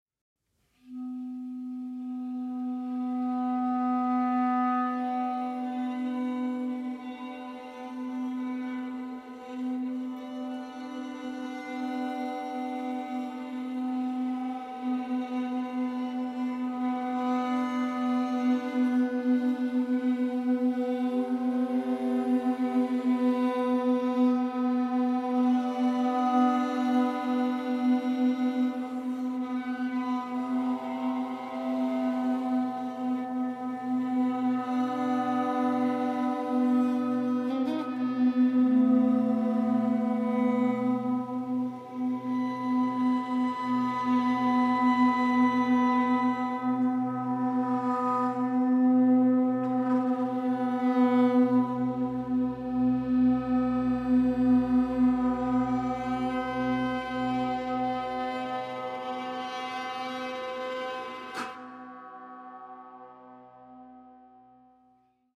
clarinet
viola
electric guitar